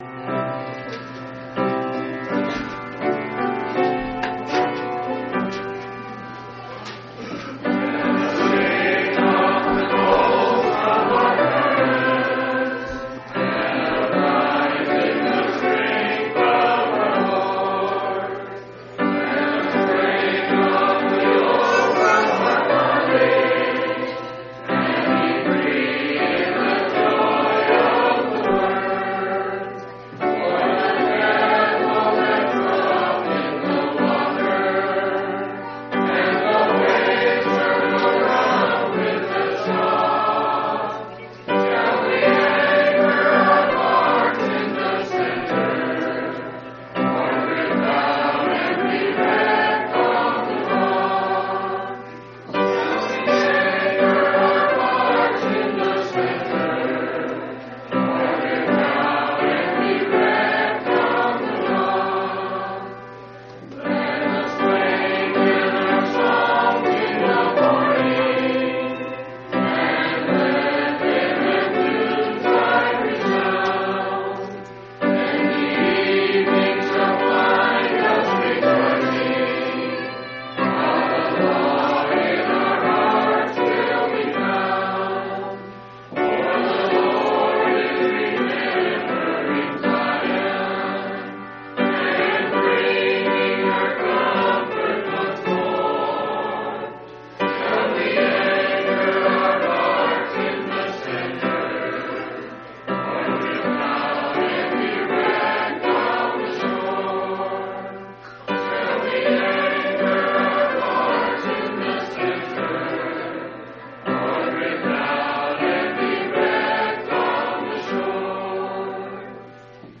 11/24/1989 Location: Phoenix Reunion Event